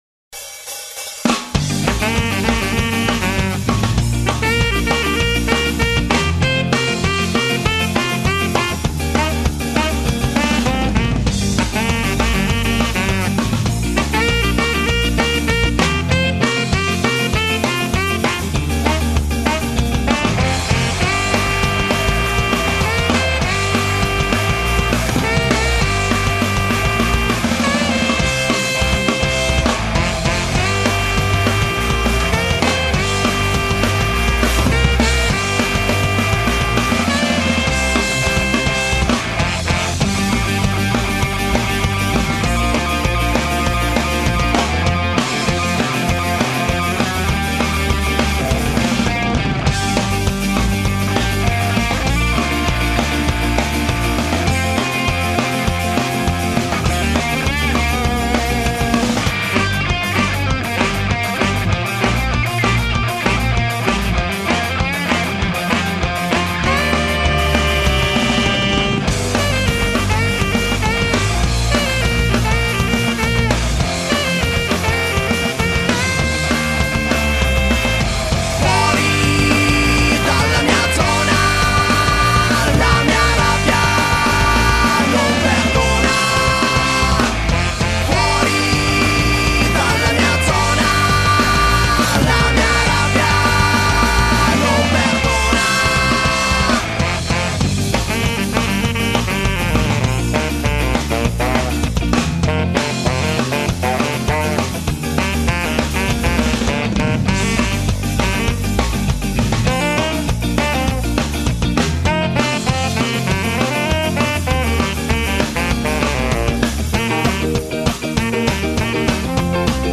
sax tenore
lo ska-core con testi rigorosamente in italiano.